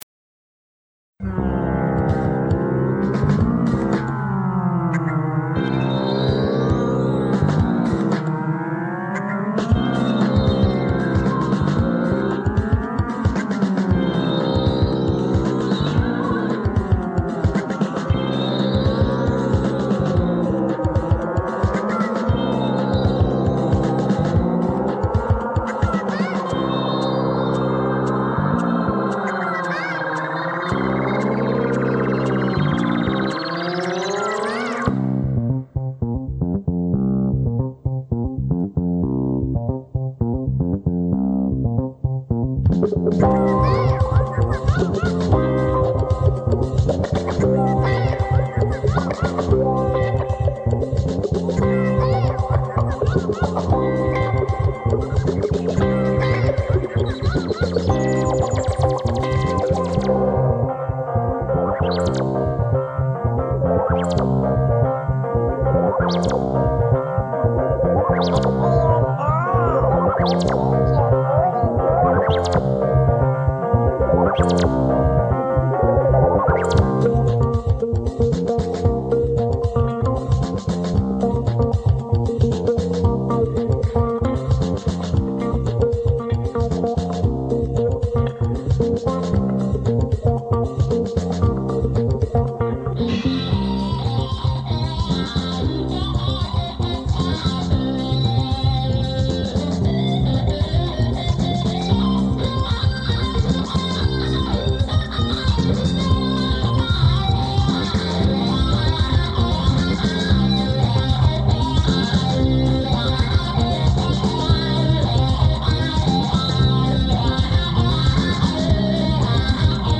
I think the engineer was expecting a live band, but we turned up with samplers, keyboards, effects units, drum machines, an old analogue synth and pretty much anything else I had lying around my home studio.
Nonetheless we did get something down to tape, managing to use pretty much every bit of equipment we had in the process.
The levels are all wrong, there's a massive hiss and the guitar was louder than Paisley (both the textile pattern AND the reverend Ian!)
Realising I didn't have anything else to put up this week, and with our conversation still in mind, I decided to fish out that old tape and, with a generous dose of modern studio magic (and a rare free evening) I managed to transform it into something that, whilst by no means brilliant, gives us something to show for that crazy night in the studio.